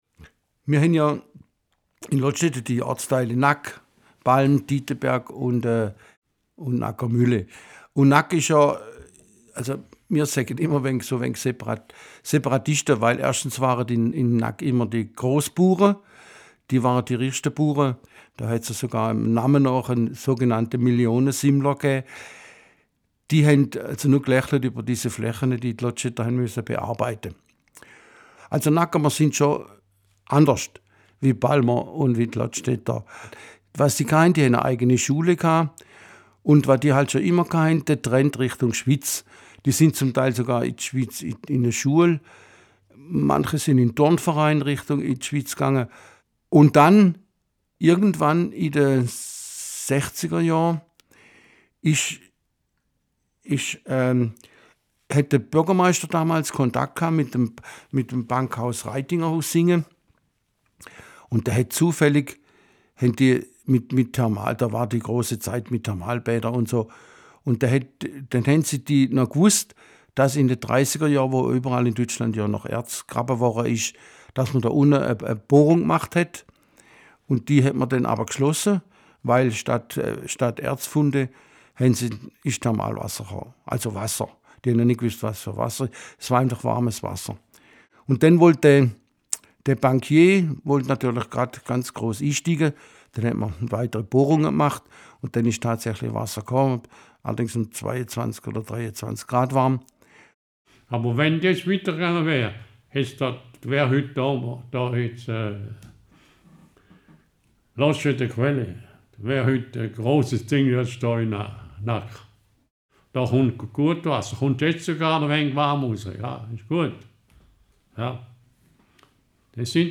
Dazu sollen Zeitzeugen, die mit den Gebäuden in Verbindung stehen, zu Wort kommen. In Tonaufnahmen mit Hilfe der Methode «Oral History» sollen diese Menschen zu ausgewählten Bauwerken erzählen und ihre persönlichen Erinnerungen und Erlebnisse teilen.